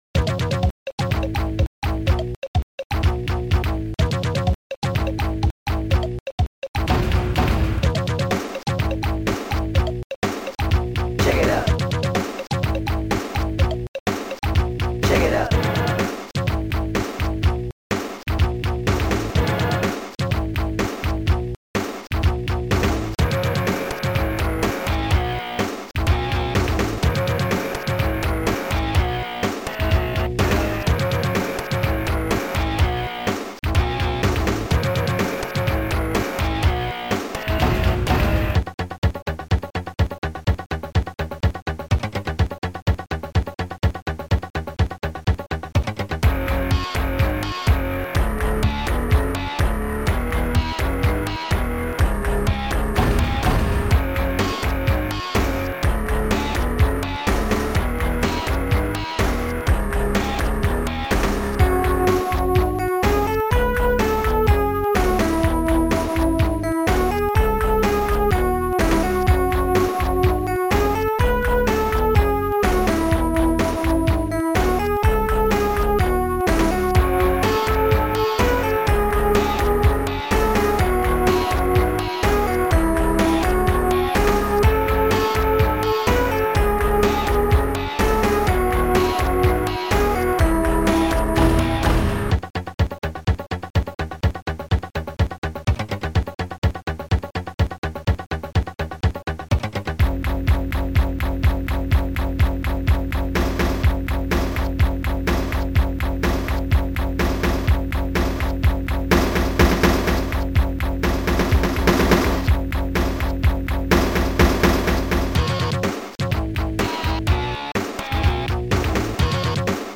Soundtracker 15 Samples